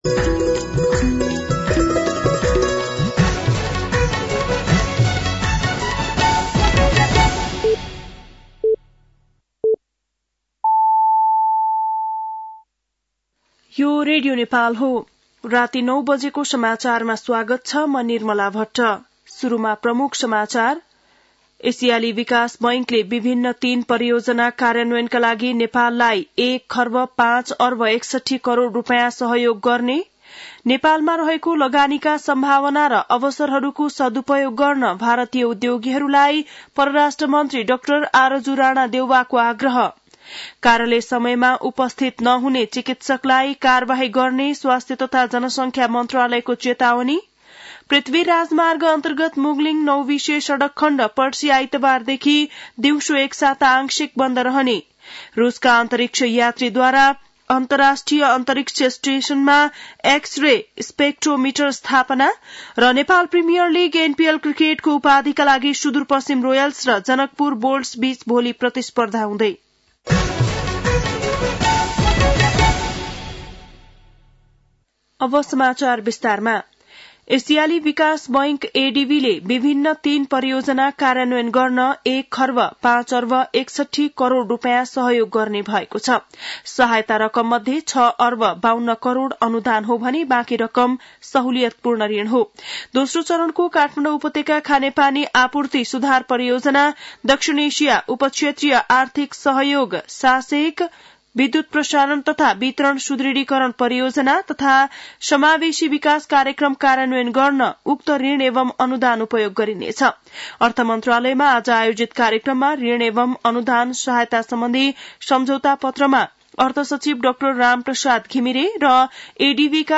बेलुकी ९ बजेको नेपाली समाचार : ६ पुष , २०८१
9-PM-Nepali-NEWS-9-05.mp3